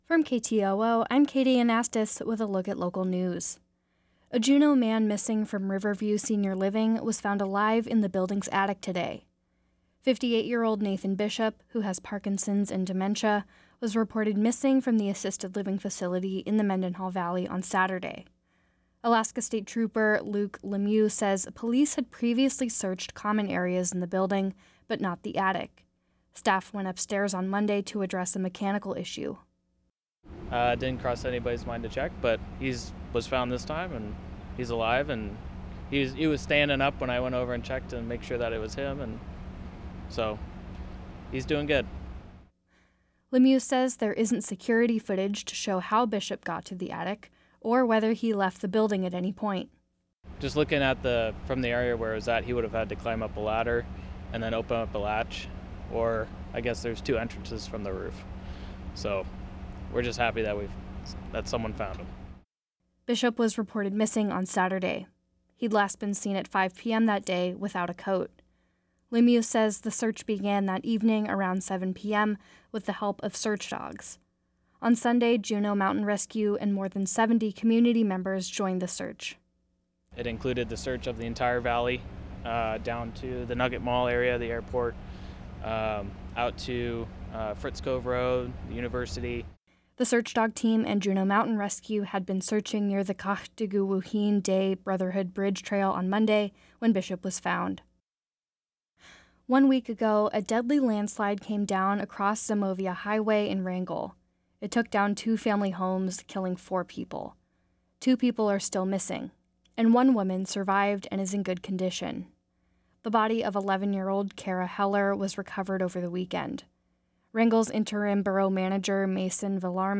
Newscast – Monday, Nov. 27, 2023